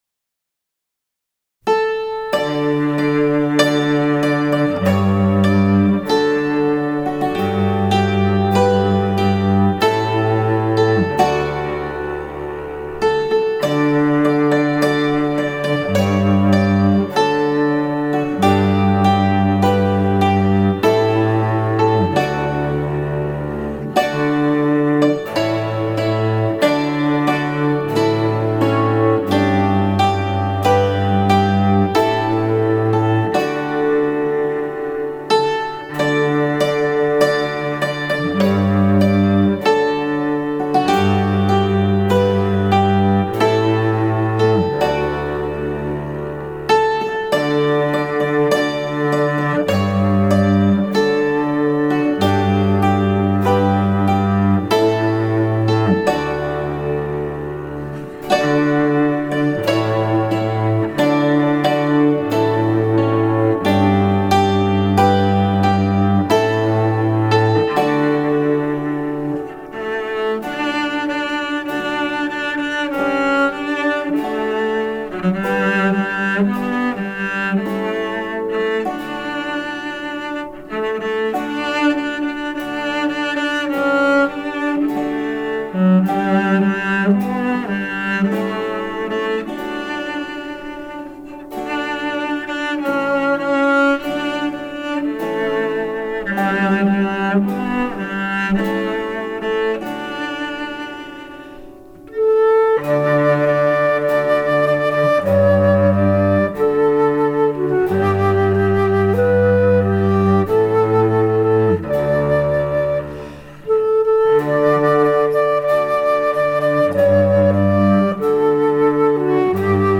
DIGITAL SHEET MUSIC - HAMMERED DULCIMER SOLO